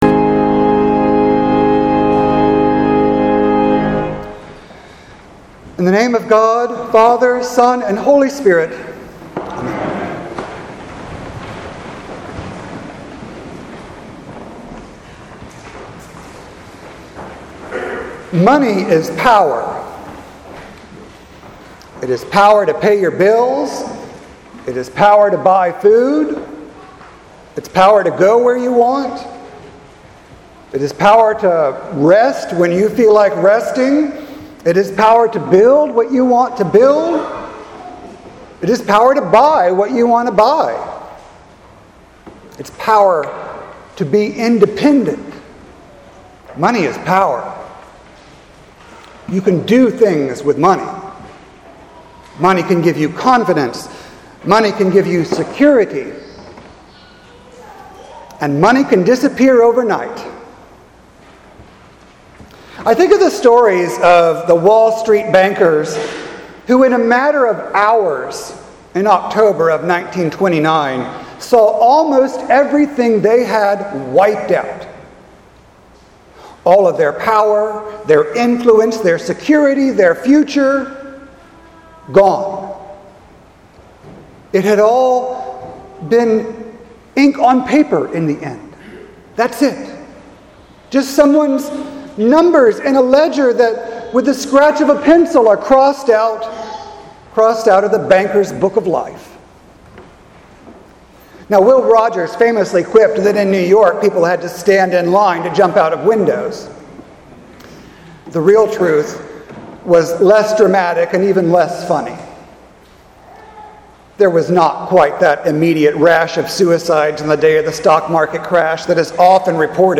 Sermon for Sunday, September 22nd, 2019
sermon-9-22-19.mp3